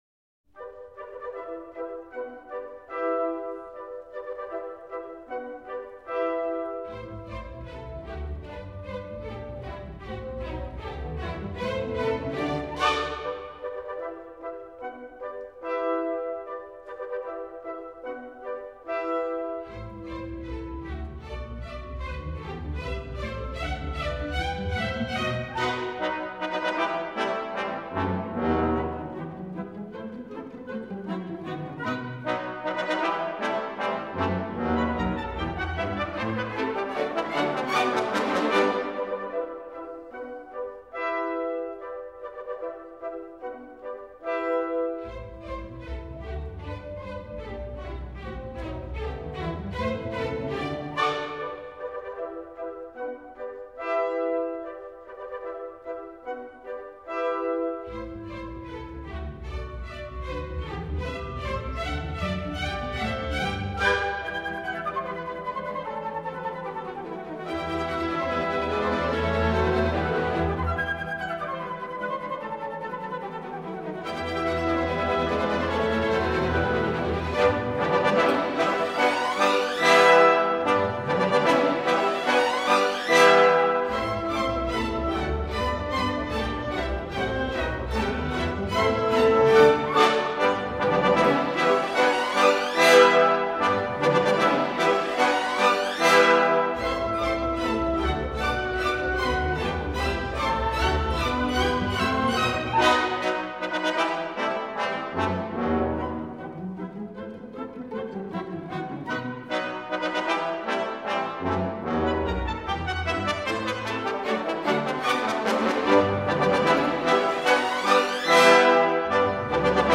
قطعه اول: مارش قطعه دوم: رقص روسی قطعه سوم: رقص اسپانیایی قطعه چهارم: رقص عربی قطعه پنجم: رقص چینی قطعه ششم: رقص رید فلوت ها قطعه هفتم: رقص فرشته آب نبات قطعه هشتم: والس گل ها
موسیقی کلاسیک